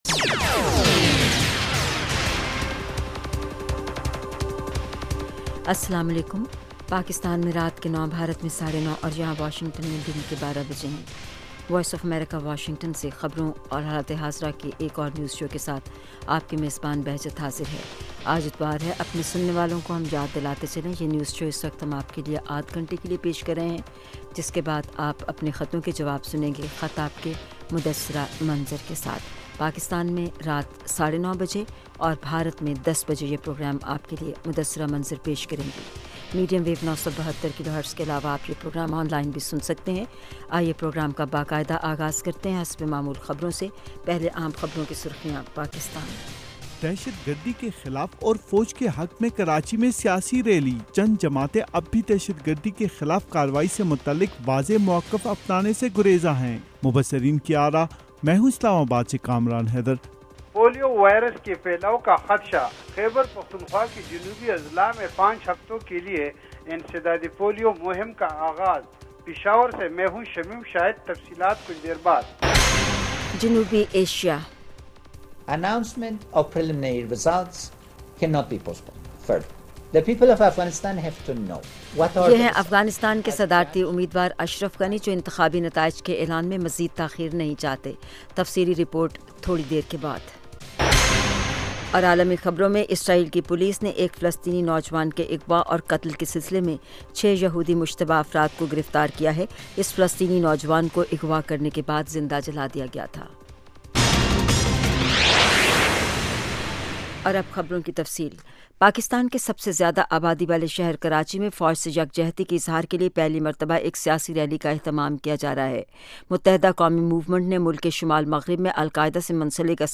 9:00PM اردو نیوز شو